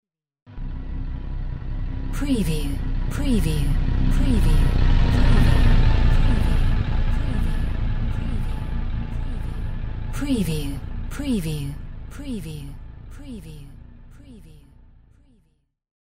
Spaceship flyby
Stereo Wav. sound effect 16 bit/44.1 KHz and Mp3 128 Kbps
PREVIEW_SCIFI_SPACESHIP_FLYBYHD01.mp3